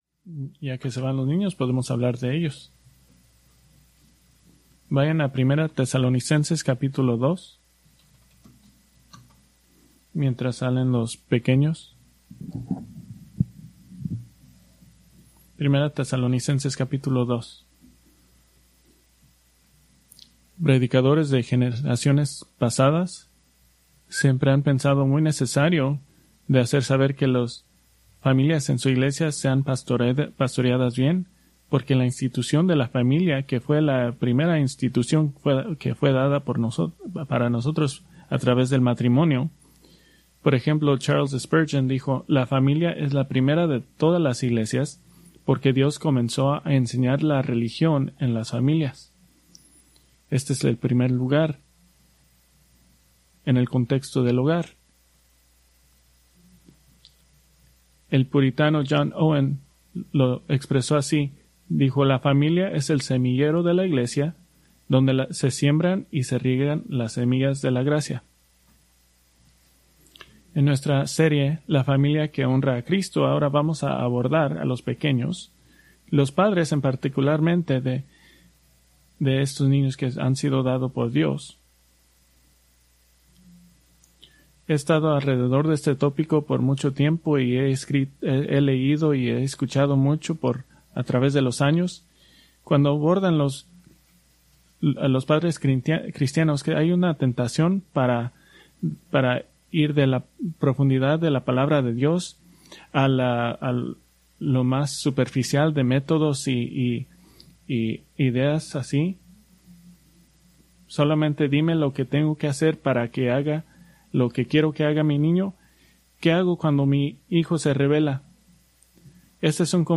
Preached August 17, 2025 from Escrituras seleccionadas